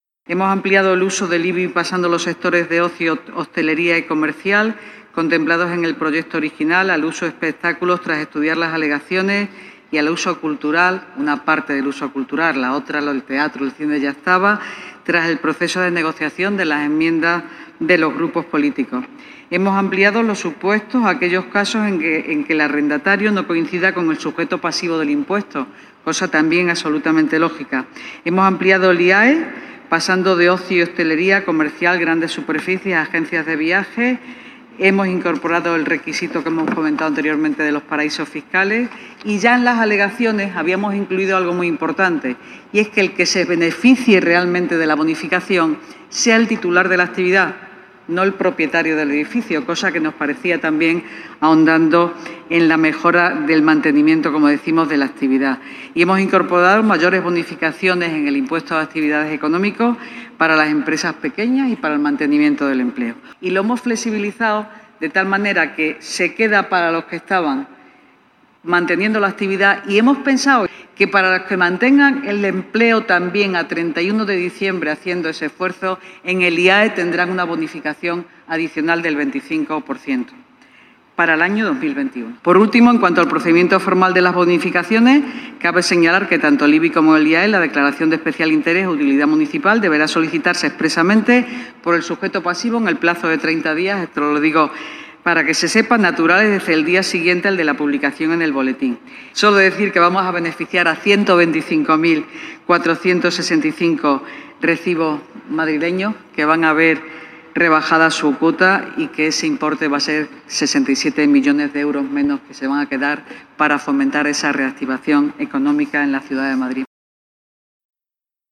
Nueva ventana:La delegada de Economía y Empleo apunta algunas de las medidas que se han tomado en sectores como la cultura o la hostelería, entre otros